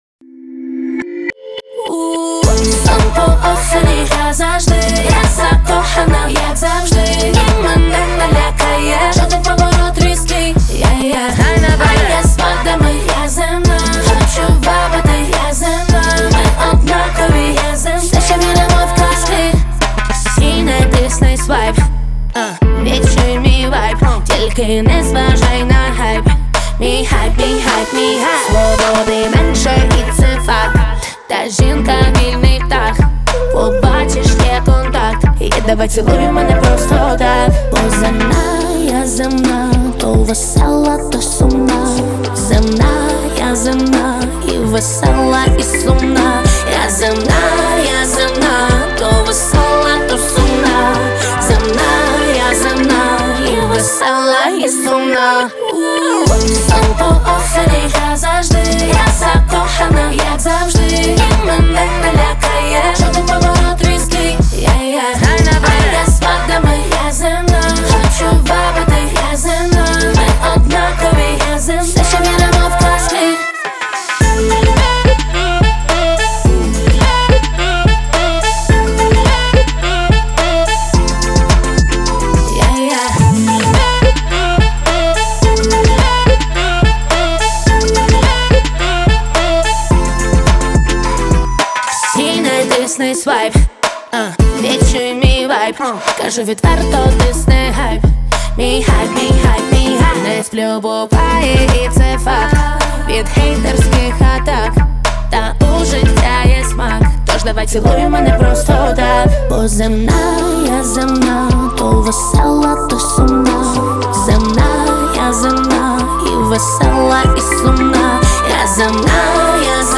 это яркий пример поп-фолка с элементами электронной музыки.